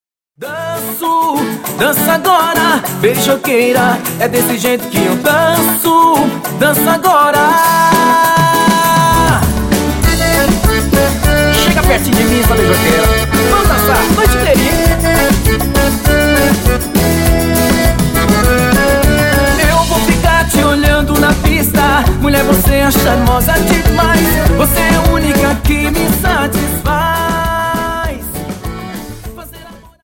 Dance: Samba 50